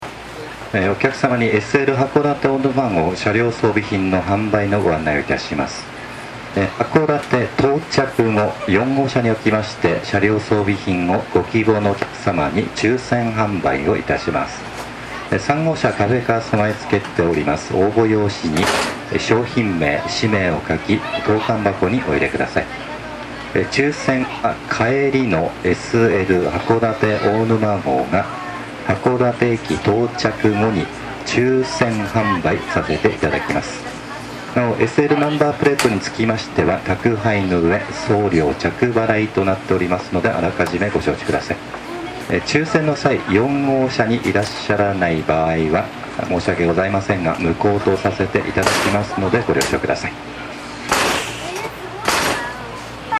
車両部品販売のアナウンス